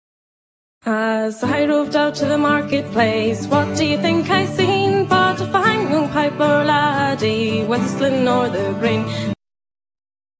(Song)
(Reel)